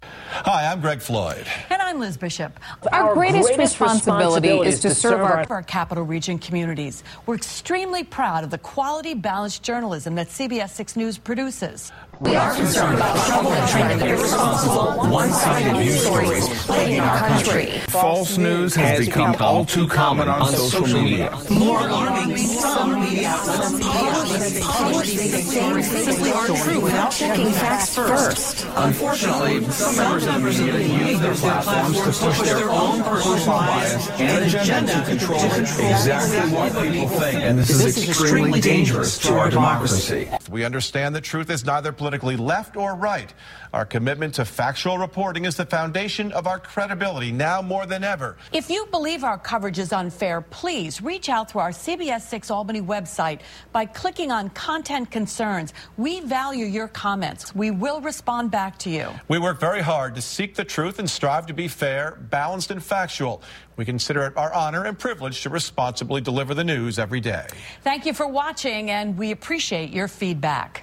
Deadspin, the sports website, was the first media outlet to collect recordings of many local Sinclair Broadcast Group stations with their anchors reading the exact same script, and put them together. Three weeks ago, CNN reported the story that local anchors were being forced to read a controversial script that parrots the president's repeated accusation of "fake news."